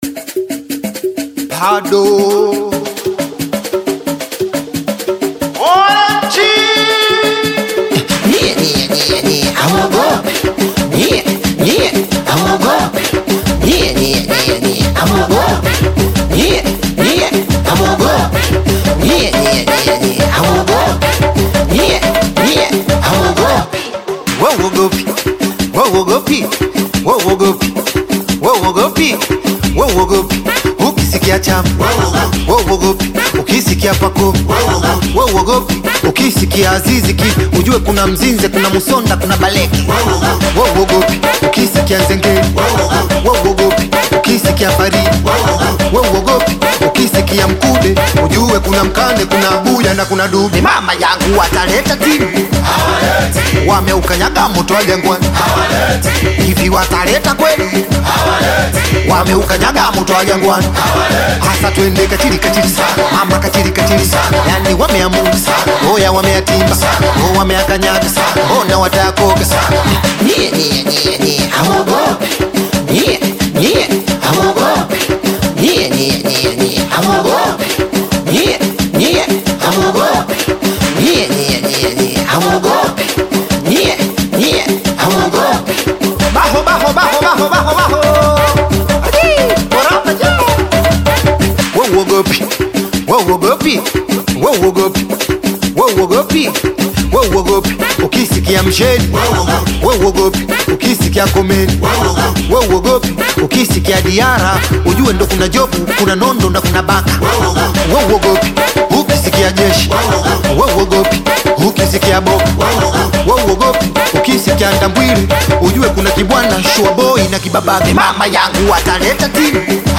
Singeli song